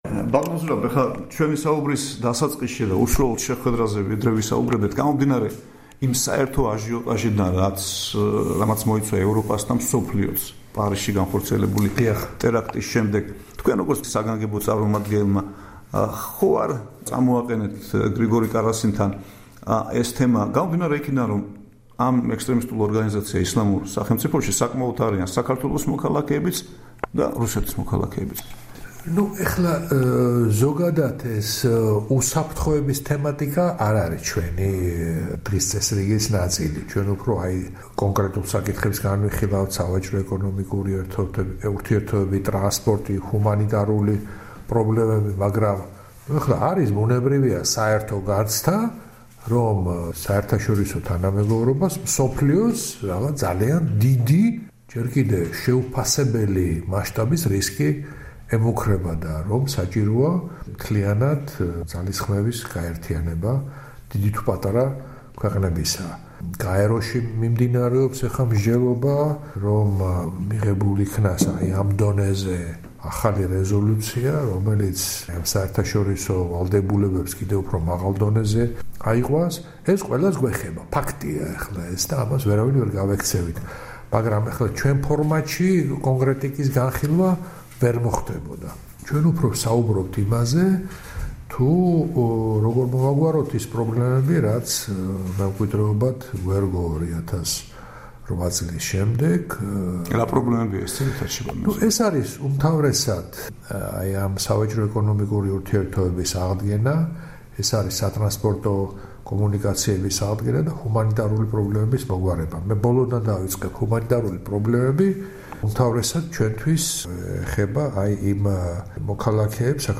ინტერვიუ ზურაბ აბაშიძესთან